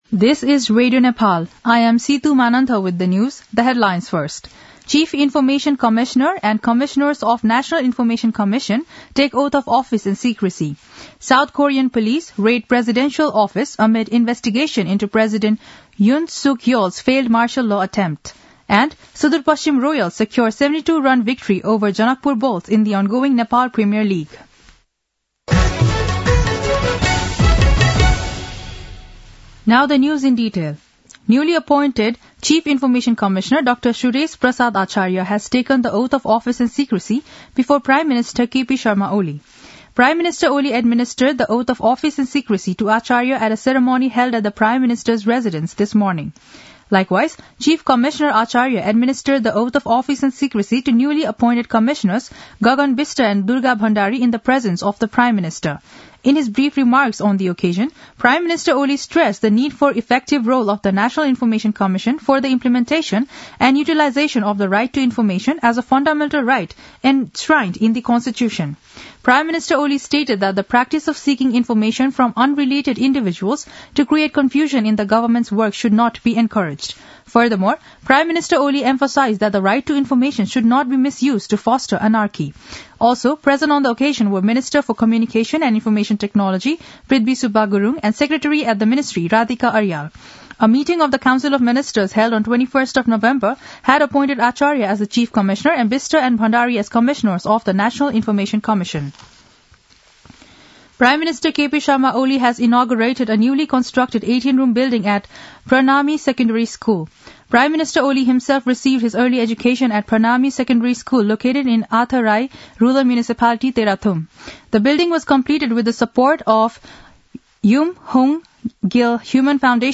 दिउँसो २ बजेको अङ्ग्रेजी समाचार : २७ मंसिर , २०८१
2-pm-english-news-1-8.mp3